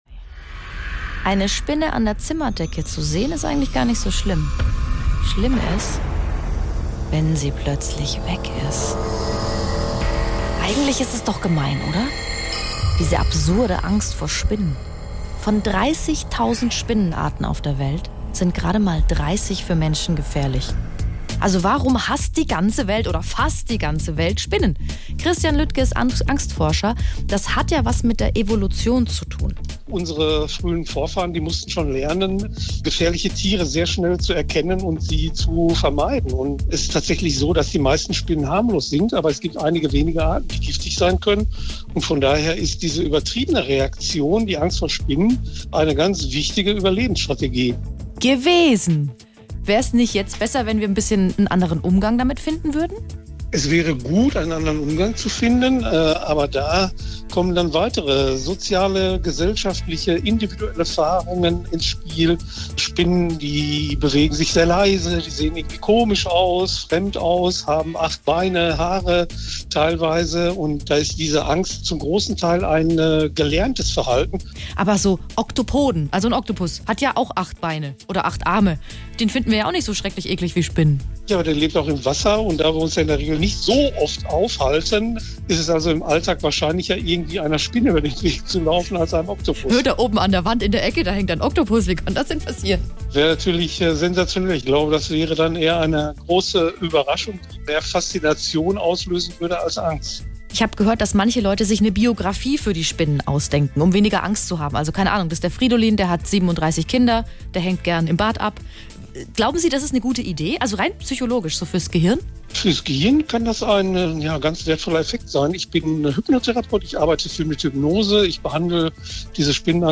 Interview Warum haben wir Angst vor Spinnen?